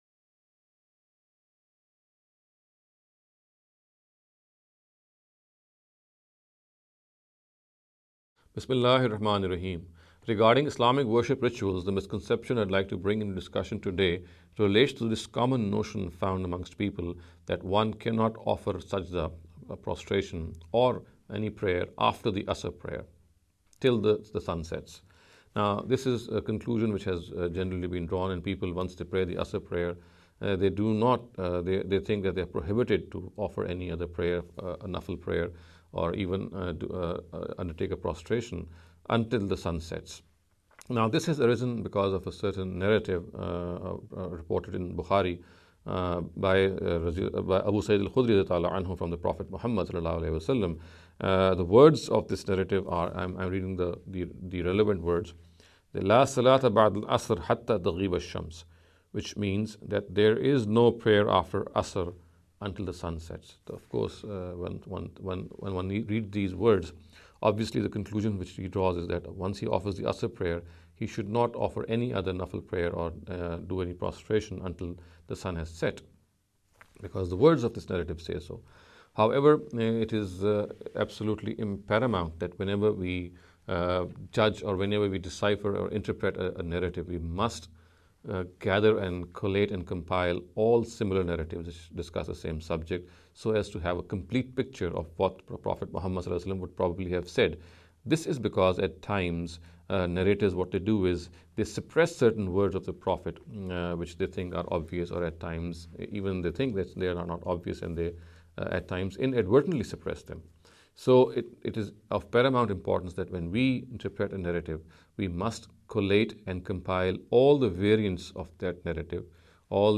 This lecture series will deal with some misconception regarding Islamic worship rituals.